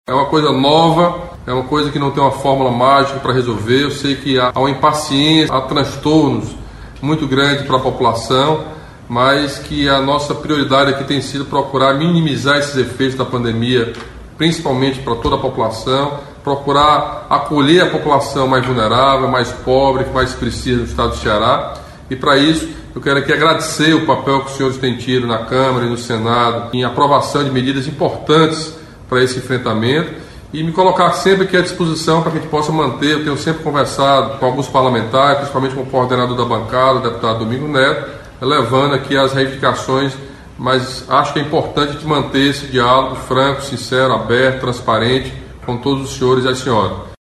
O governador Camilo Santana se reuniu nesta segunda-feira (20), por meio de videoconferência, com deputados federais e senadores para discutir ações de combate ao coronavírus no Ceará.